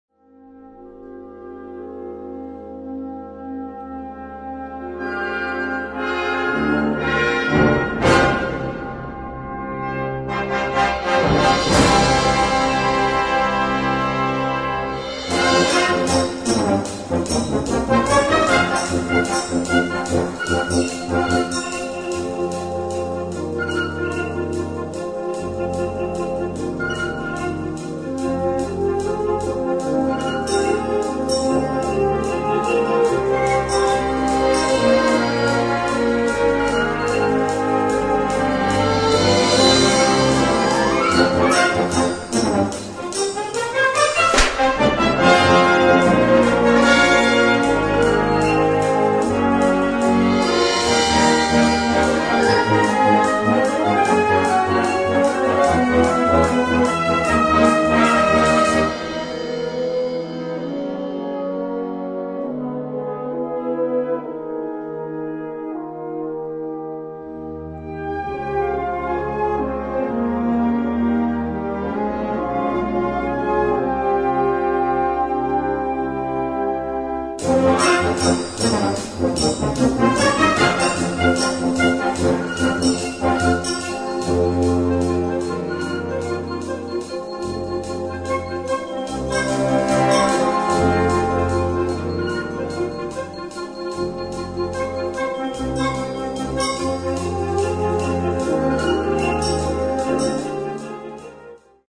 Instrumentation: Blasorchester
Sparte: Sinfonische Musik